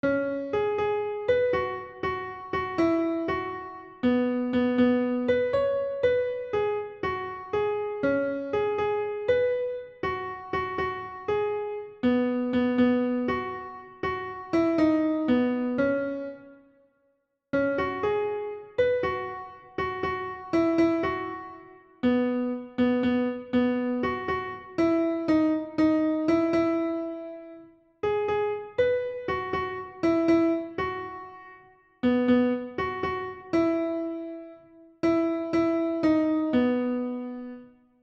大家听DEMO可以感受到，最终出来的音乐跟最初人类作曲家的动机旋律是完全匹配的。